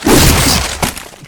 combat / abilities / double swipe / flesh3.ogg
flesh3.ogg